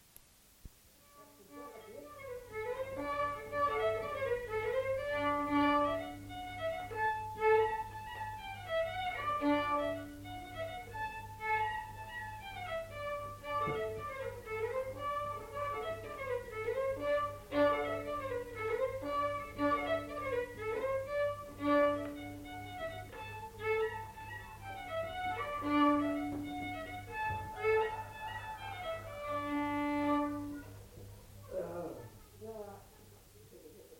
Rondeau
Aire culturelle : Lomagne
Genre : morceau instrumental
Instrument de musique : violon
Danse : rondeau